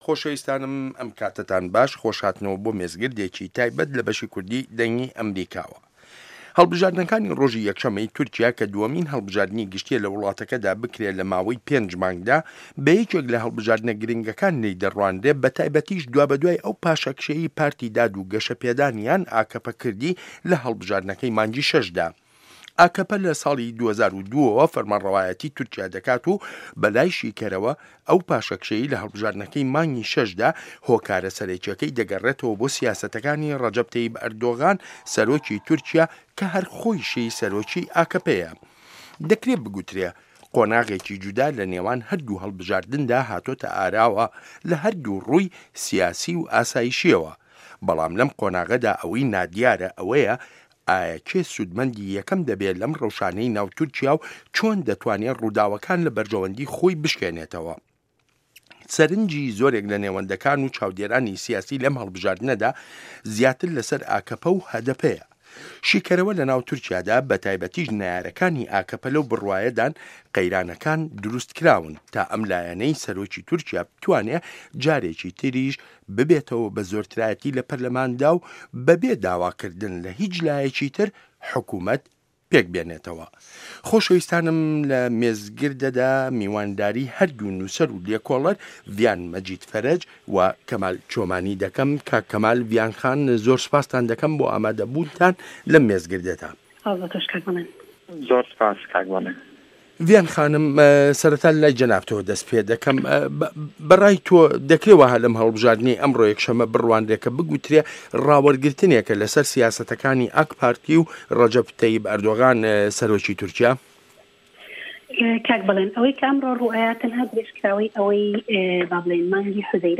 مێزگرد: هەڵبژاردنی تورکیا